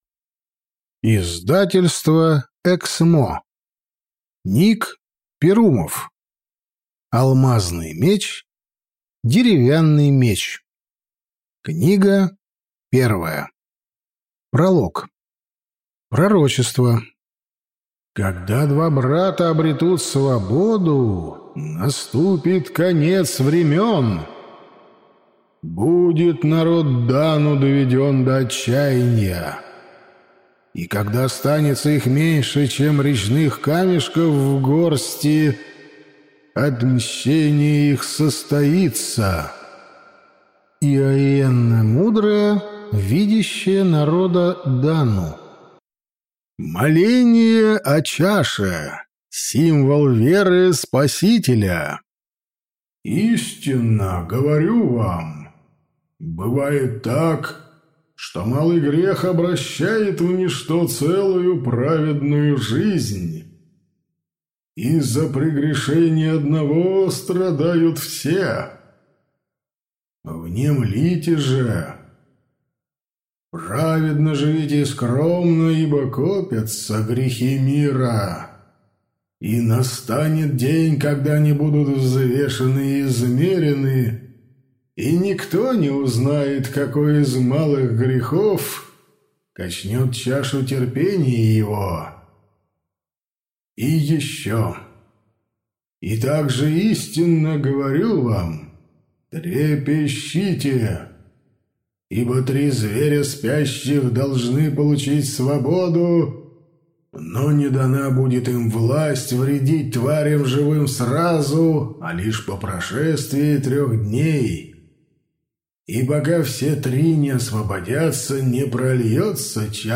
Аудиокнига Алмазный Меч, Деревянный Меч. Том 1 | Библиотека аудиокниг